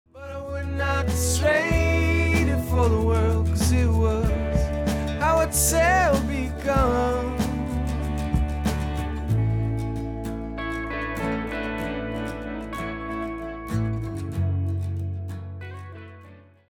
Living-Loving-UNMASTERED-SAMPLE.mp3